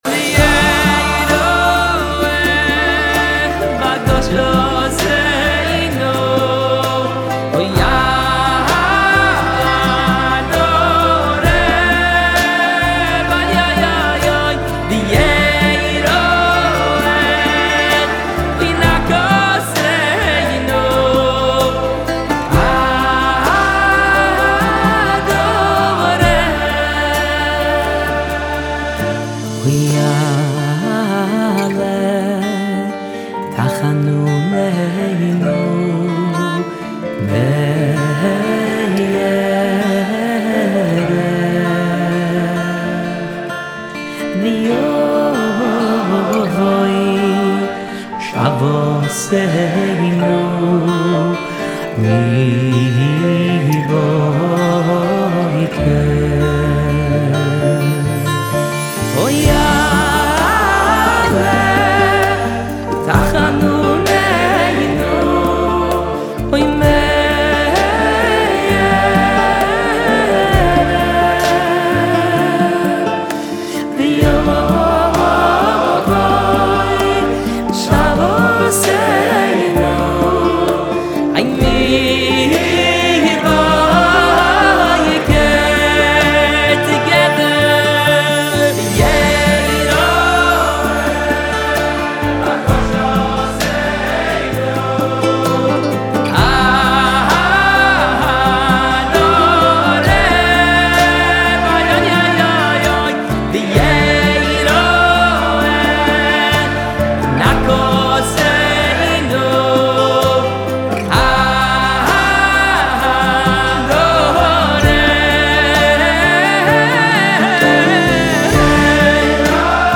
אלבום חי [בסגנון קומזיץ
מוזיקה יהודית